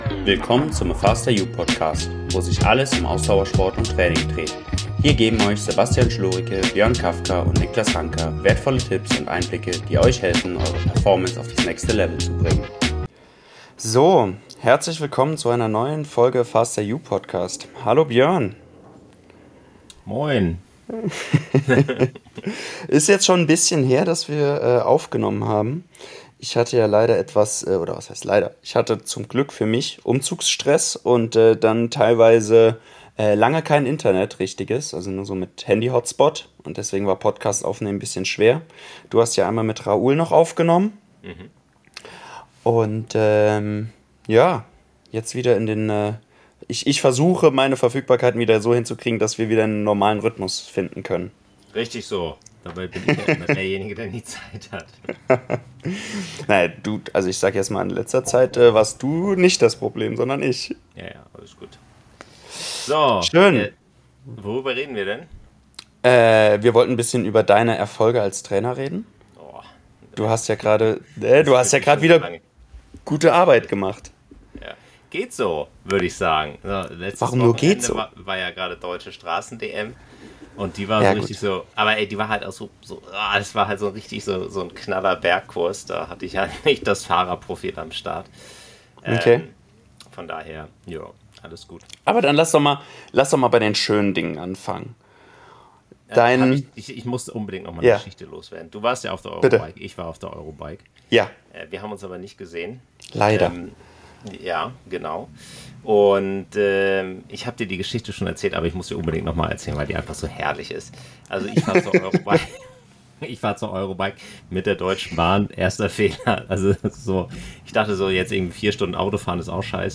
Aufgrund technischer Probleme mit der Internetverbindung mussten wir leider einen kleinen, etwas abrupten...